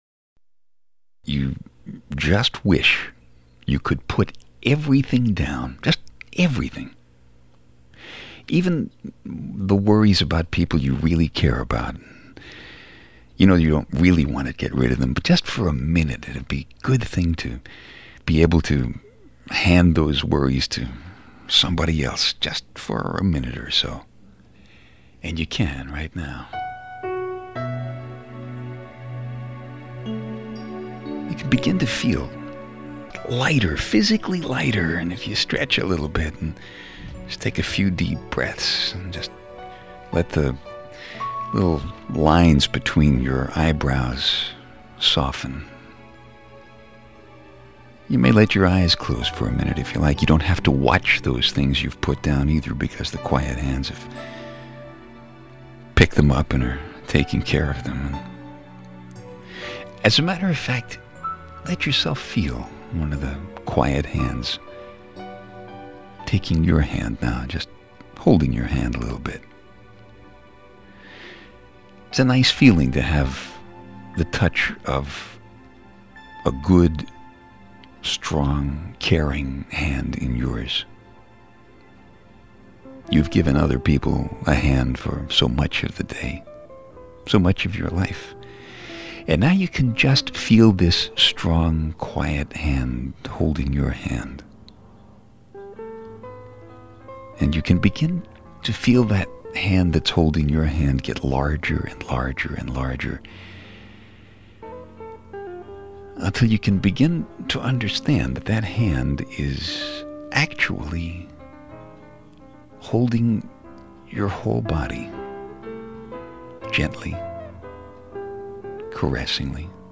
As you listen to his words you start to feel the comfort of the Quiet Hands soothing you.
His tone is warm and sincere, and he responds to the demands that modern life makes on our capacities to feel and to desire.